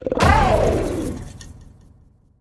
Cri de Koraidon dans sa forme Monture dans Pokémon Écarlate et Violet.
Cri_1007_Monture_EV.ogg